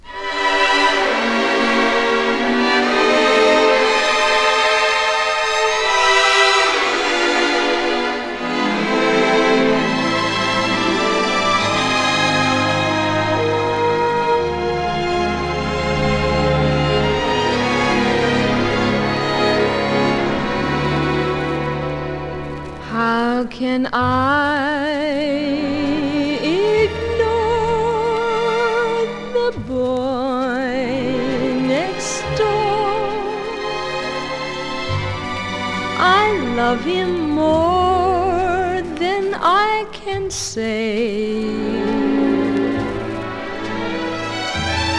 Pop, Vocal, Stage & Screen　USA　12inchレコード　33rpm　Stereo